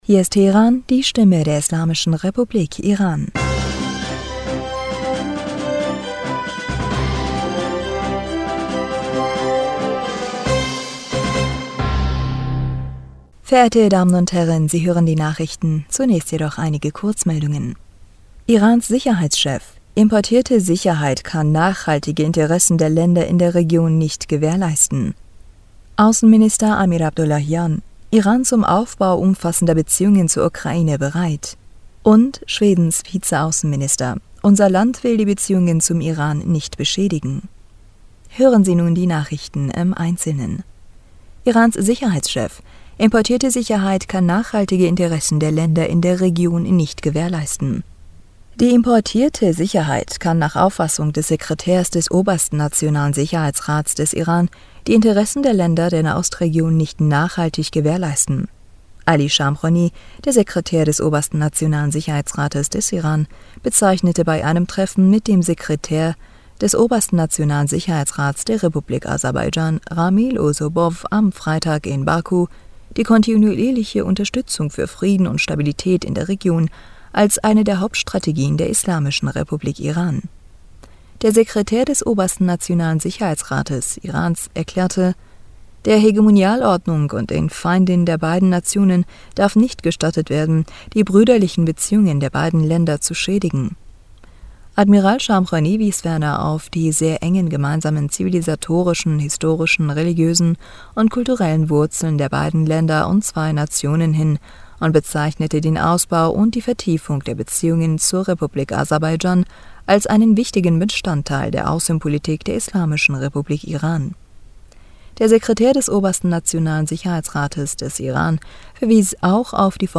Nachrichten vom 16. Juli 2022